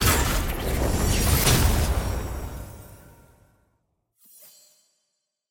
sfx-tier-wings-promotion-to-iron.ogg